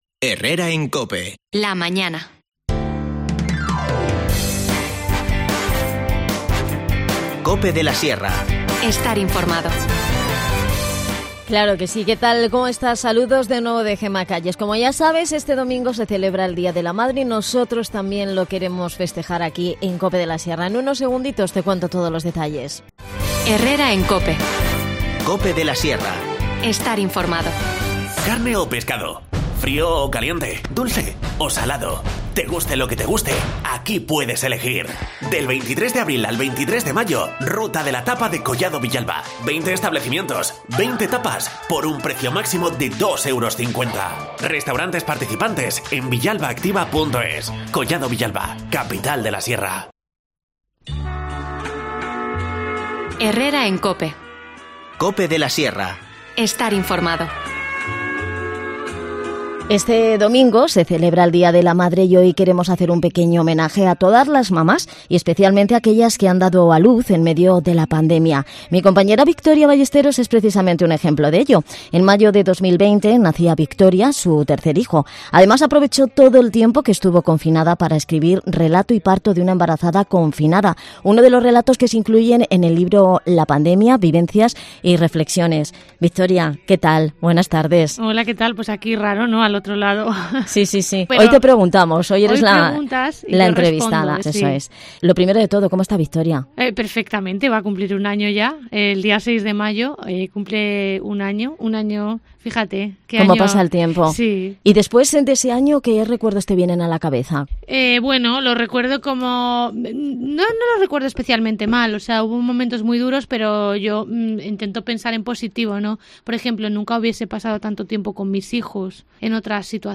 Charlamos con ella en el programa.